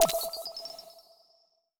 overlay-pop-in.wav